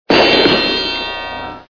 Cartoon Crash Into Metal